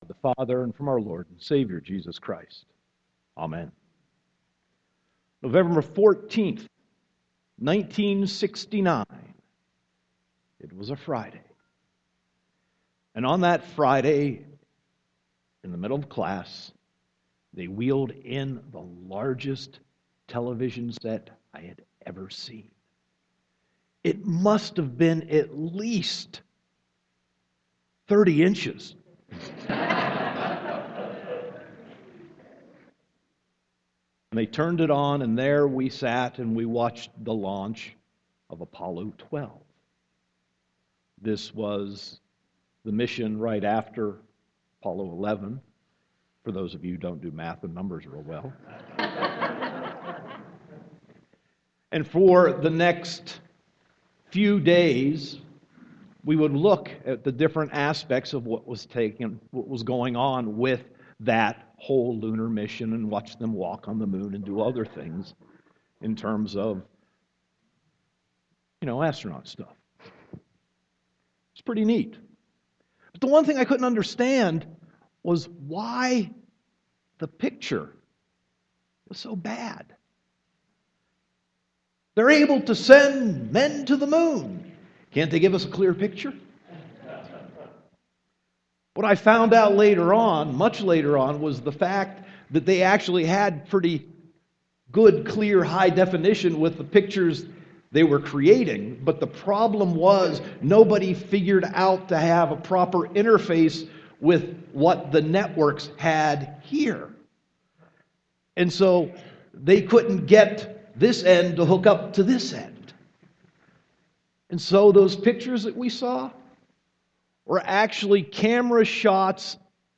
Sermon 4.19.2015